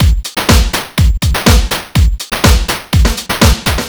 123 Lo Pressing Full.wav